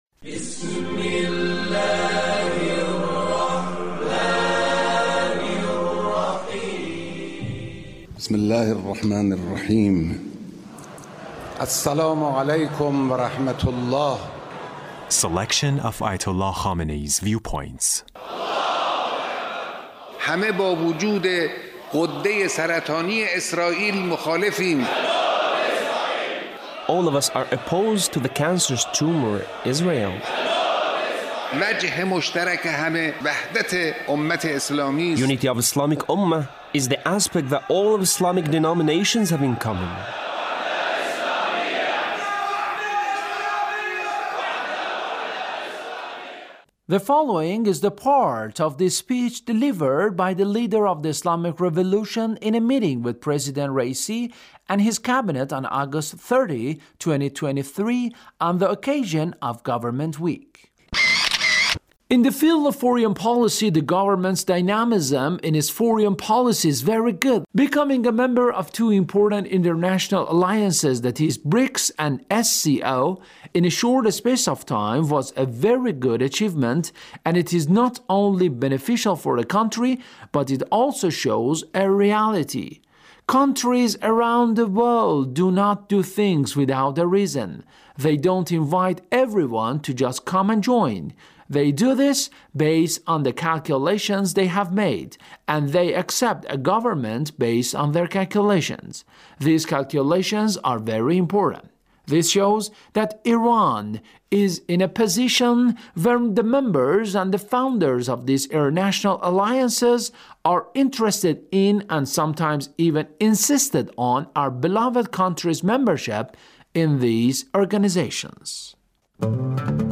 Leader's Speech with Government Officials